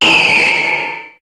Cri de Méga-Altaria dans Pokémon HOME.
Cri_0334_Méga_HOME.ogg